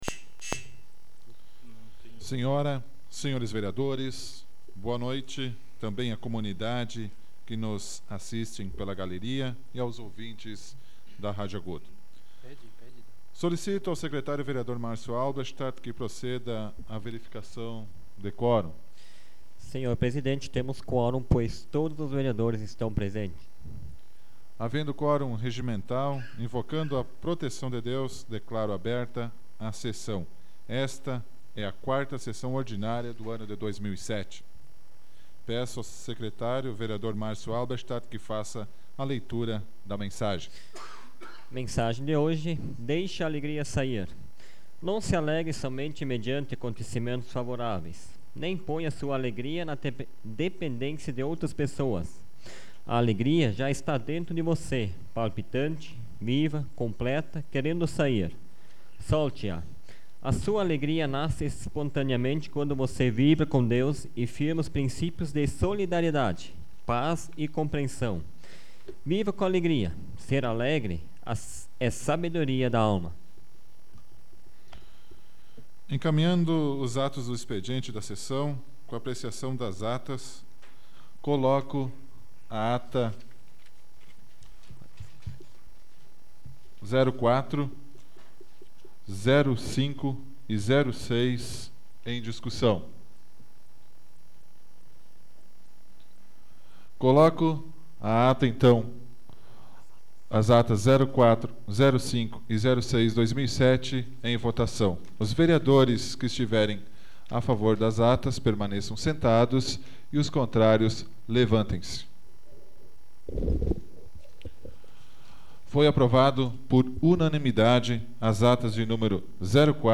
Áudio da 78ª Sessão Plenária Ordinária da 12ª Legislatura, de 26 de março de 2007